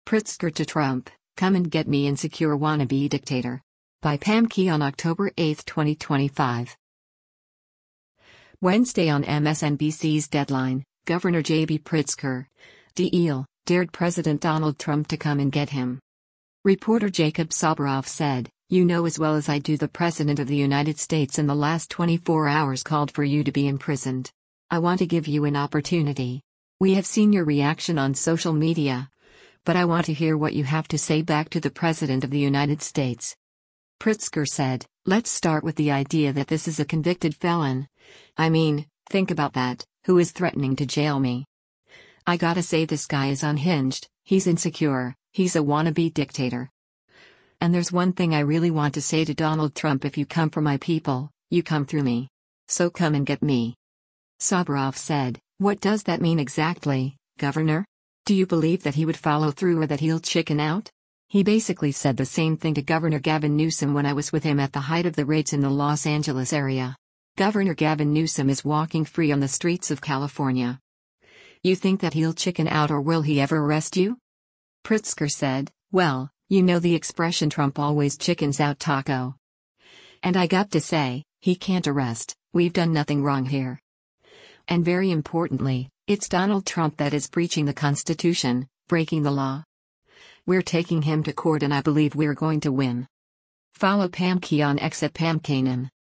Wednesday on MSNBC’s “Deadline,” Gov. JB Pritzker (D-IL) dared President Donald Trump to “come and get him.”